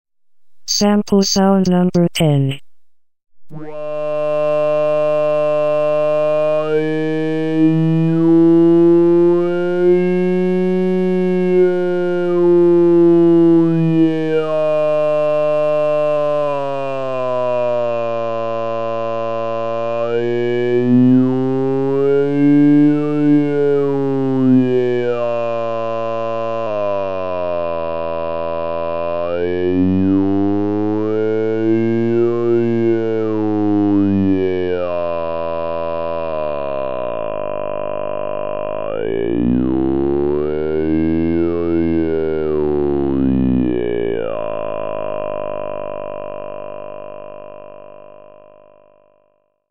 ●フォルマントフィルタ（
録音では中心となるピッチを4段階にわたって移動させ、それぞれ7種類の 母音に相当するフォルマント周波数をさらに移動させてある。